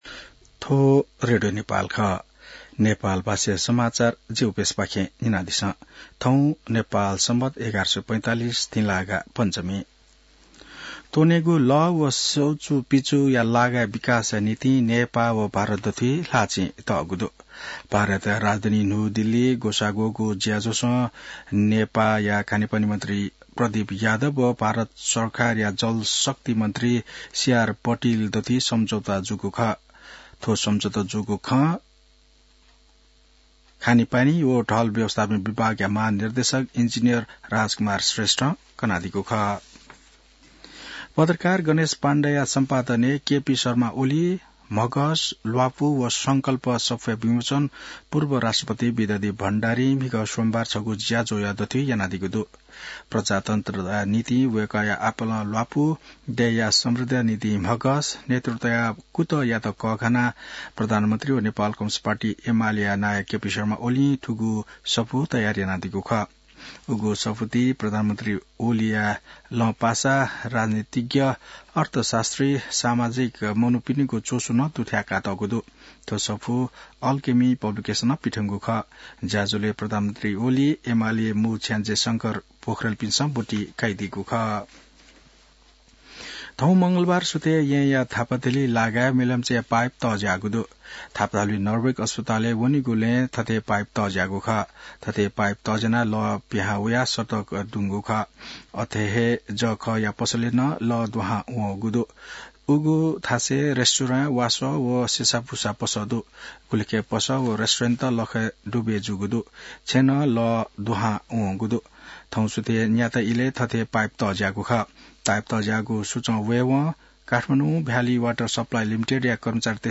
नेपाल भाषामा समाचार : २१ फागुन , २०८१